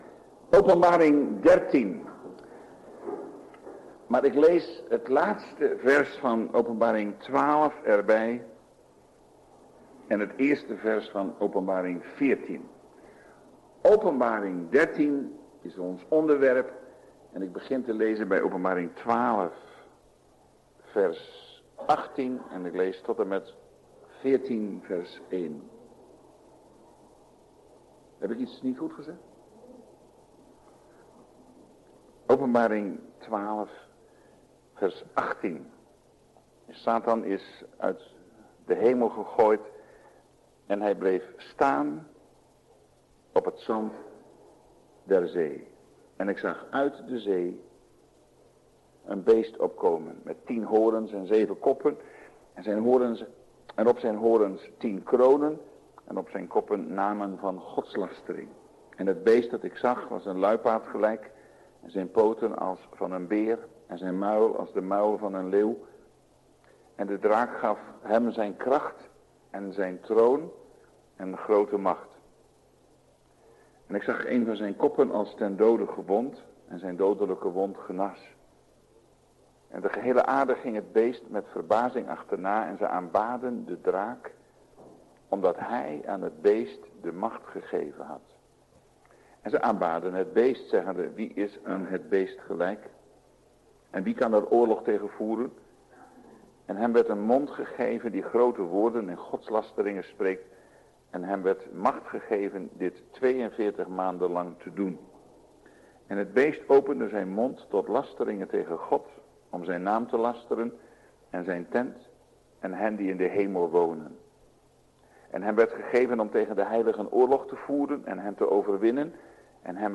Na ongeveer 45 minuten (wanneer kant A overgaat naar kant B) kan een korte stilte voorkomen.